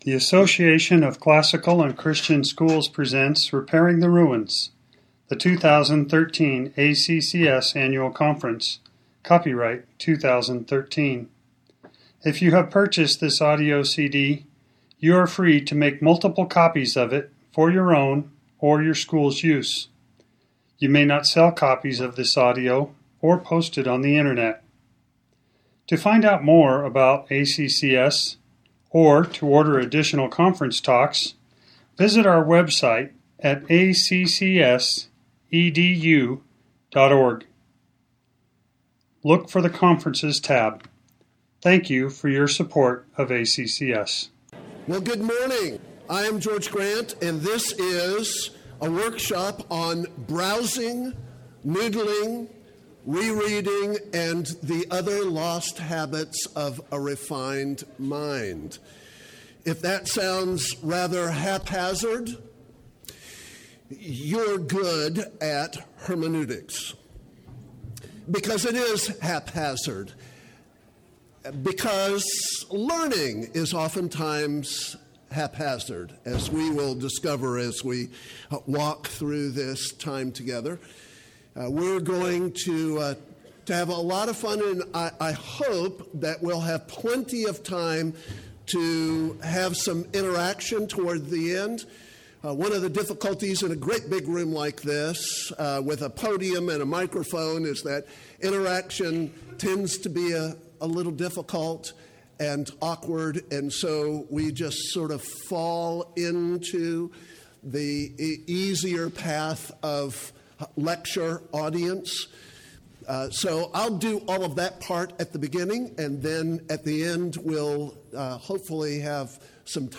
2013 Workshop Talk | 0:59:49 | All Grade Levels